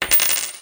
getGold2.mp3